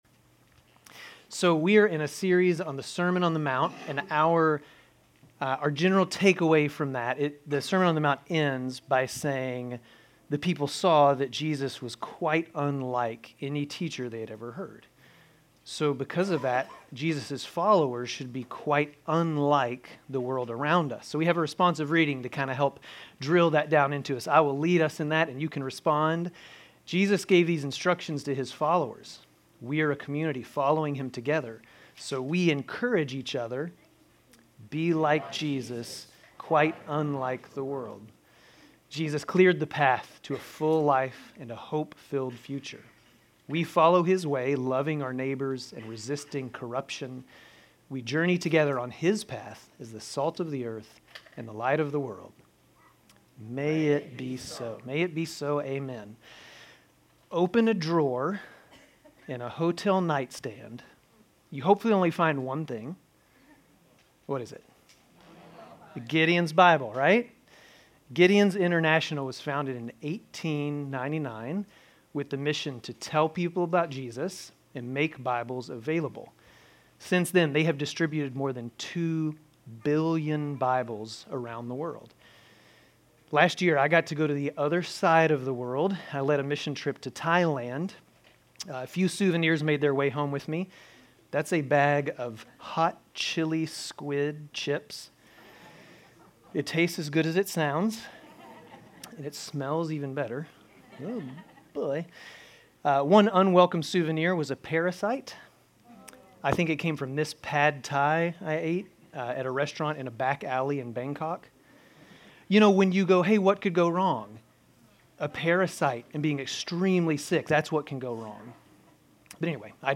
Grace Community Church Dover Campus Sermons 2_9 Dover Campus Feb 09 2025 | 00:22:58 1x 00:00 / 00:22:58 Subscribe Share RSS Feed Share Link Embed